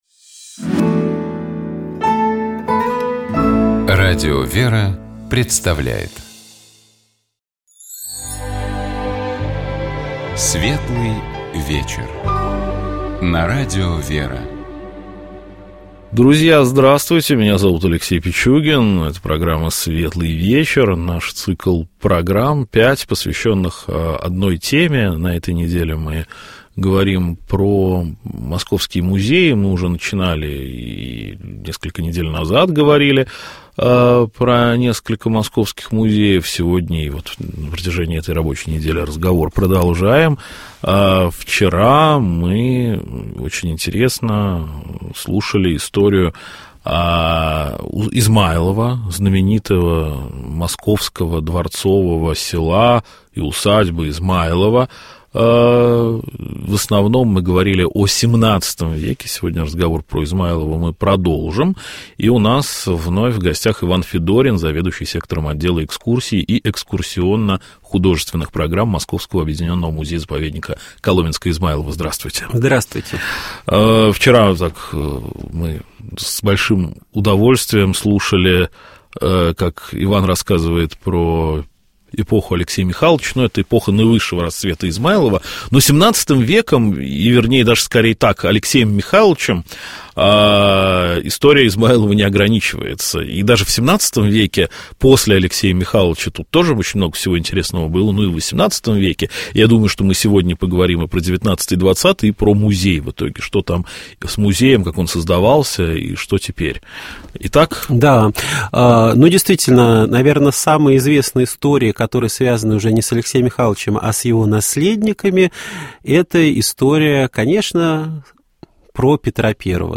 В начале 2016-го года программа «Светлый вечер» на радио ВЕРА принимала у себя в гостях поэта и публициста